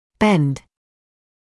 [bend][бэнд]изгибать, изгибаться; сгибаться; изгиб